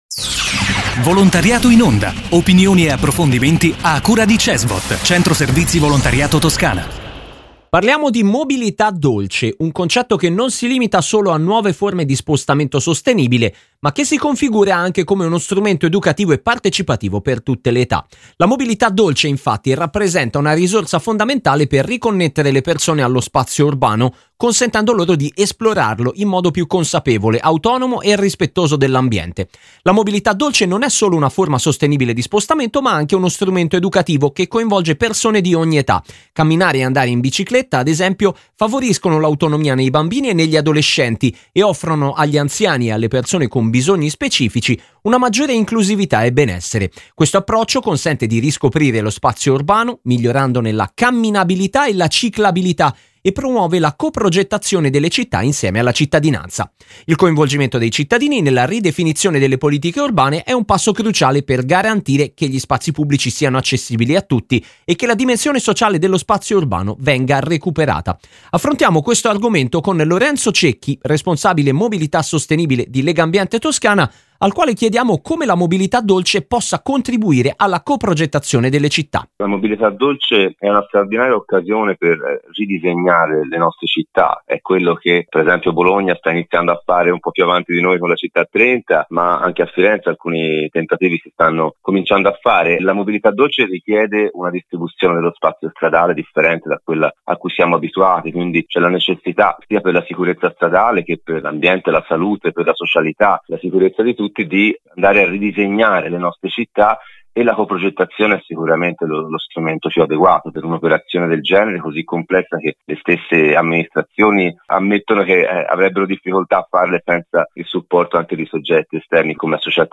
La mobilità dolce, rappresenta una risorsa fondamentale per riconnettere le persone allo spazio urbano, consentendo loro di esplorarlo in modo più consapevole, autonomo e rispettoso dell’ambiente. L'intervista